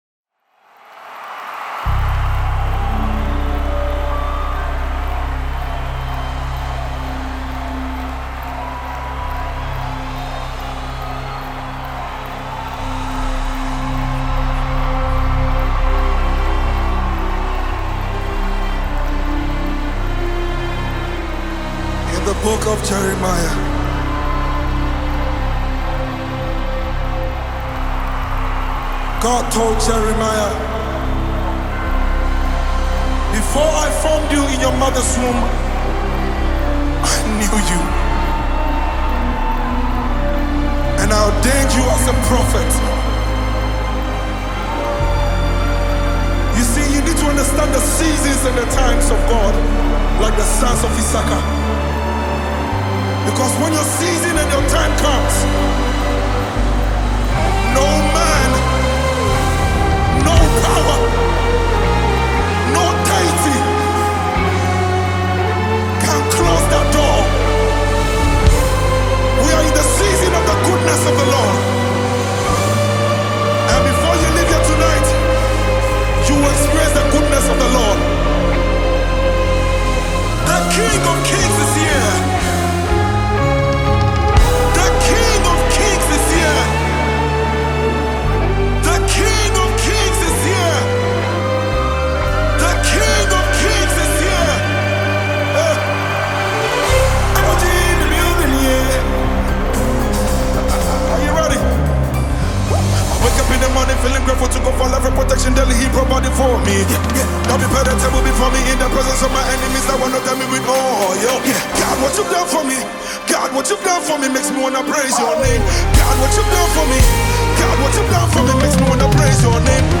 January 17, 2025 Publisher 01 Gospel 0